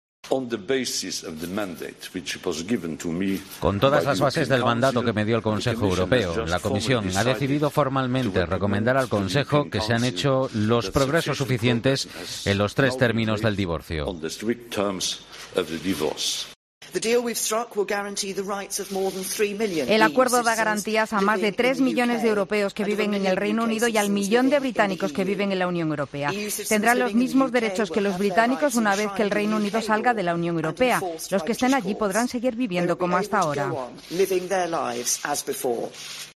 La pirmera ministra británica,Theresa May y el presidente de la Comisión Europea, Jean-Claude Juncker , en rueda de prensa por el 'brexit'